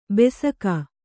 ♪ besaka